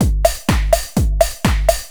DRUMLOOP058_PROGR_125_X_SC3.wav